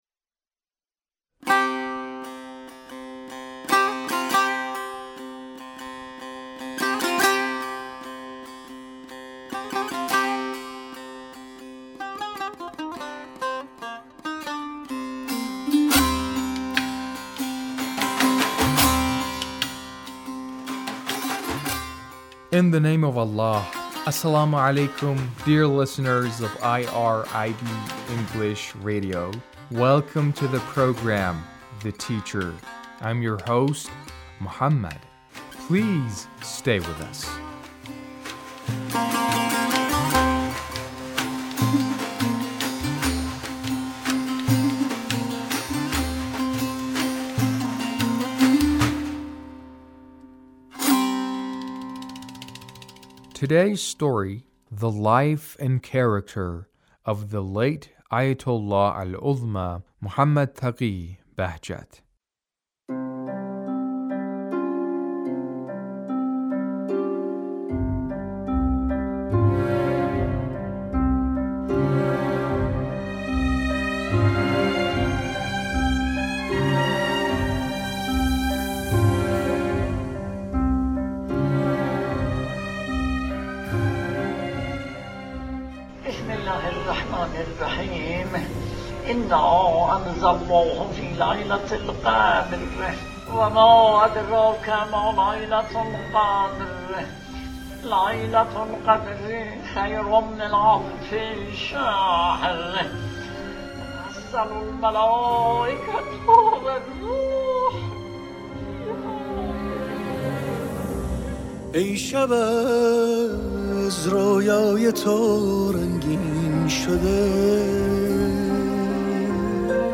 A radio documentary on the life of Ayatullah Bahjat